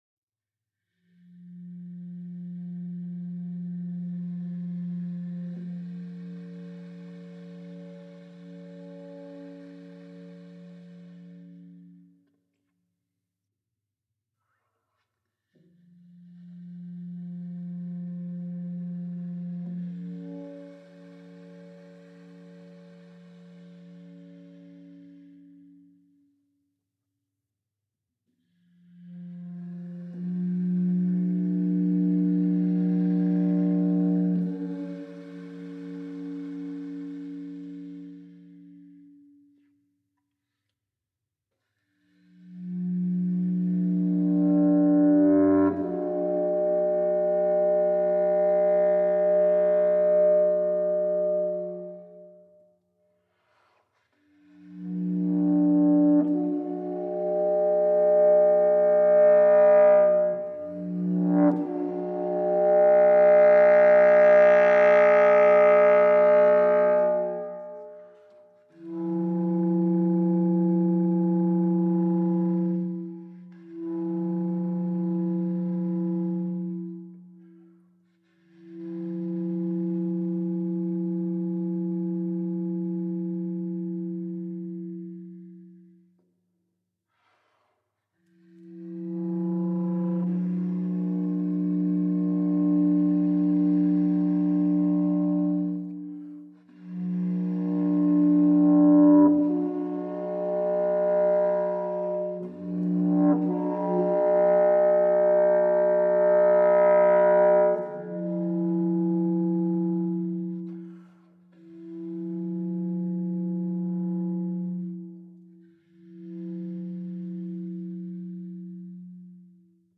…as far as the eye can see… (baritone saxophone)